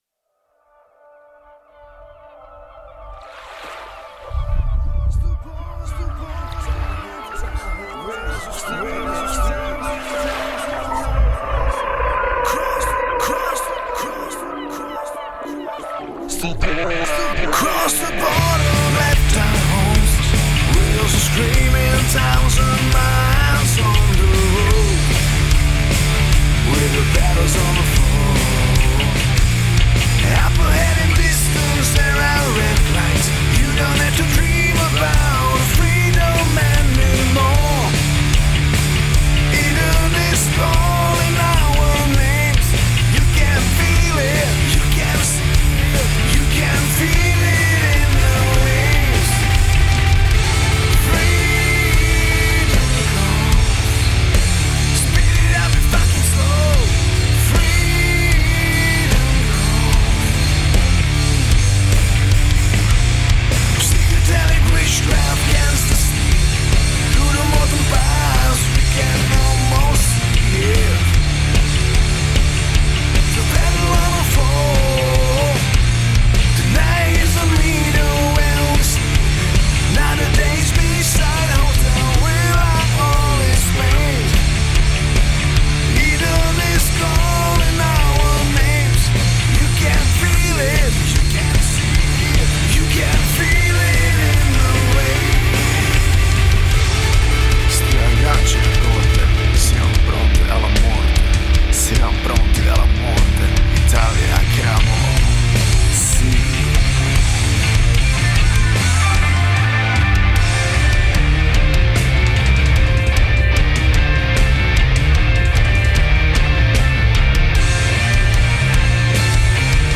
Žánr: Rock
Pop/Stoner Rock